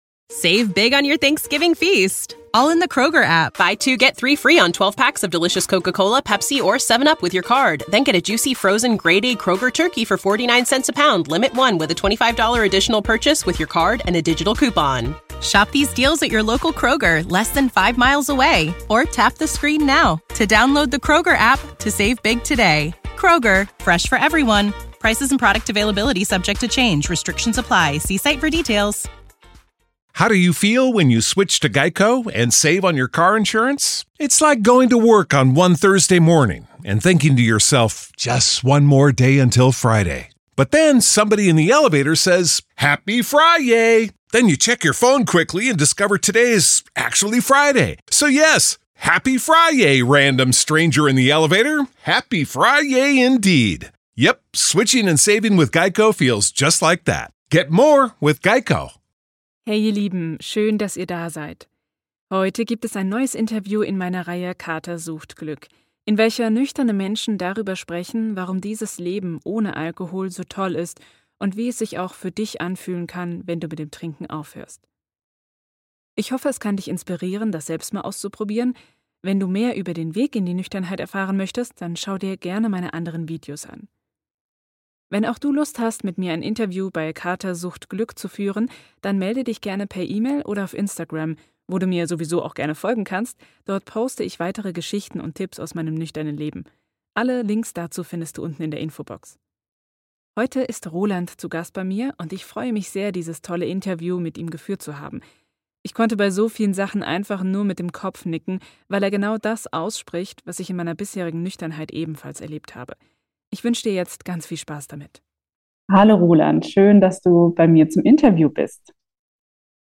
Ich hoffe, das Interview gefällt euch - schreibt mir das gerne mal in die Kommentare!